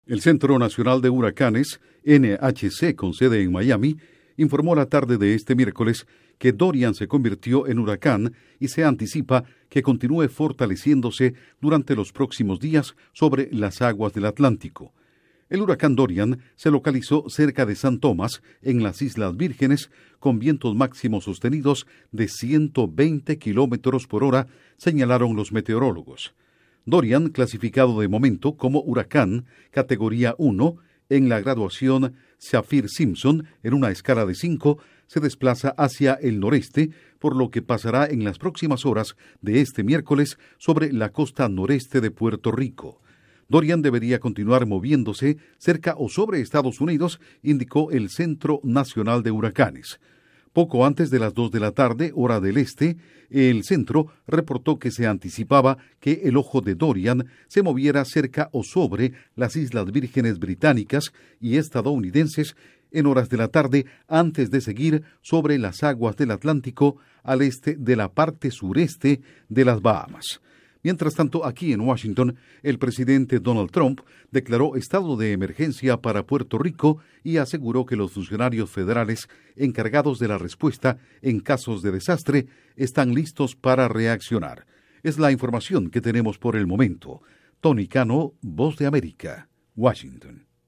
Intro: Dorian se convierte en huracán categoría uno antes de llegar a las Islas Vírgenes. Informa desde la Voz de América en Washington